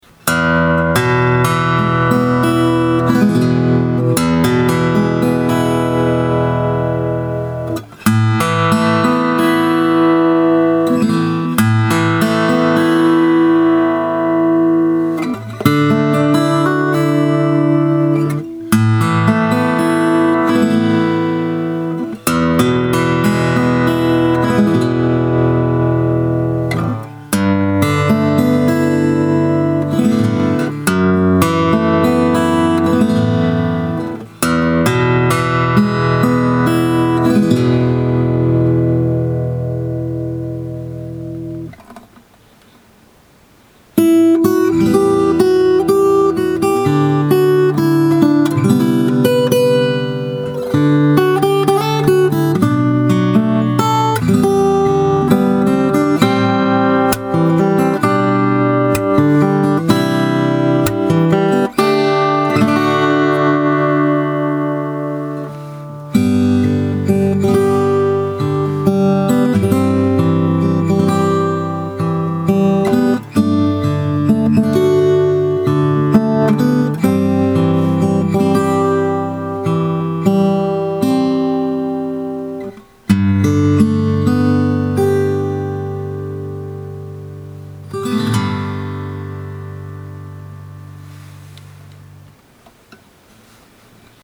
生音でのリバーブが長いのです。
はじいた弦の音があとからあとからあふれて来るような感じです。
(o⌒．⌒o) ウムウム ＳＵＭＩ ハカランダドレッド Ｄ－４５ｔｙｐｅの音 このリバーブ感はいかがでしょう。
ドレッドなのにソロでもイケる感じです。